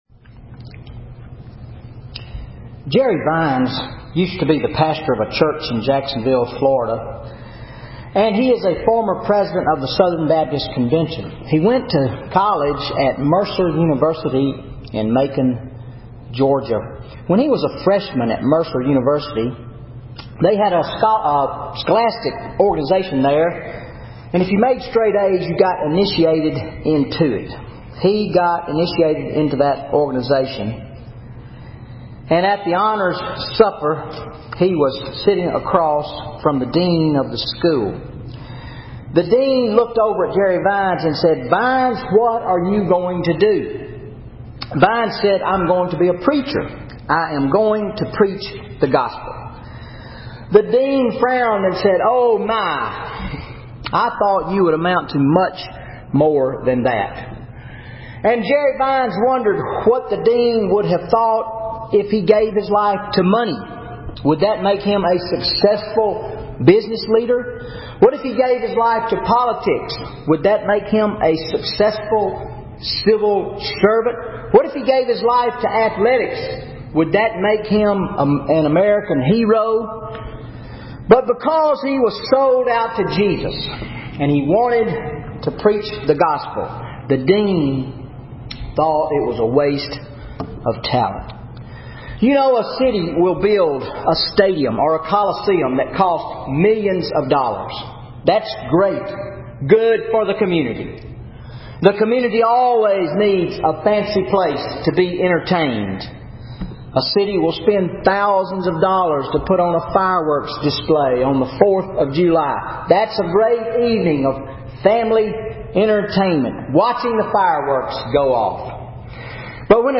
Sermon Mark 14:1-11 How Can I Demonstrate My Love for Jesus?